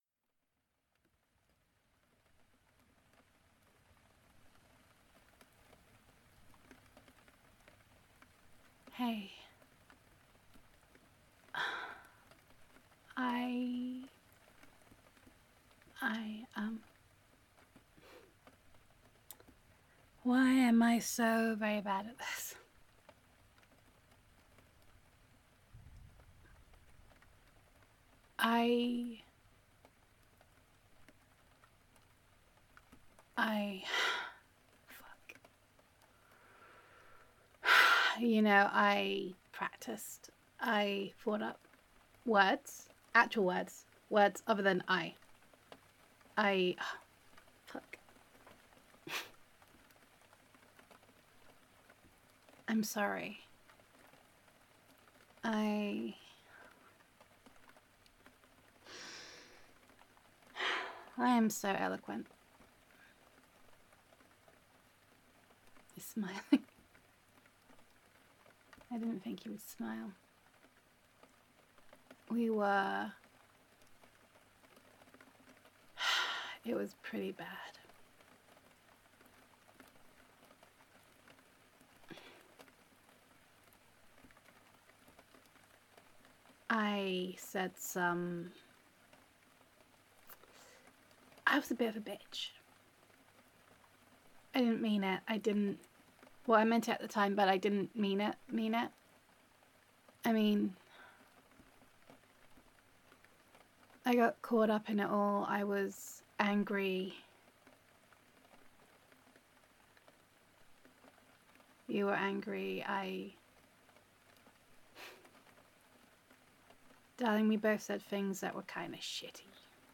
[F4A] After the Fight [Kisses][Comfort][Tears][Making Up][Argument Aftermath][Gender Neutral][Girlfriend Roleplay]